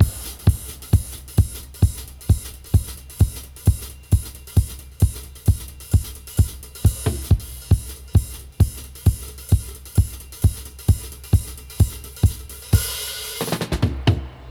134-DUB-01.wav